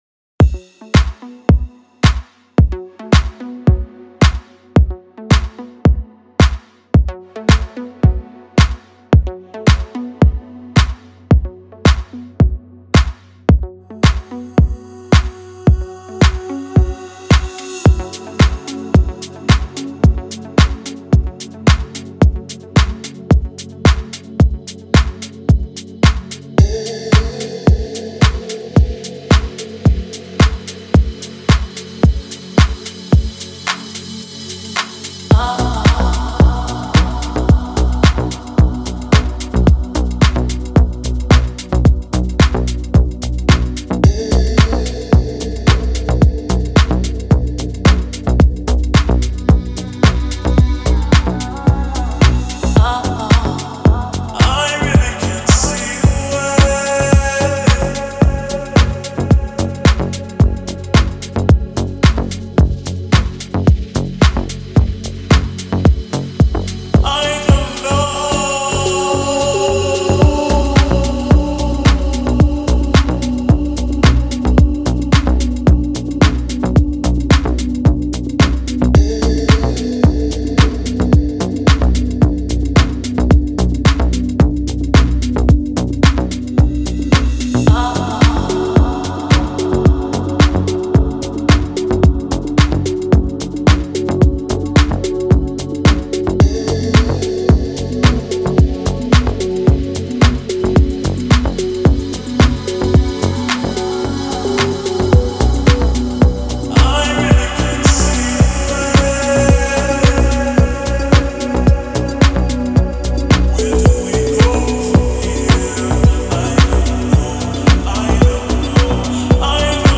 Deep House музыка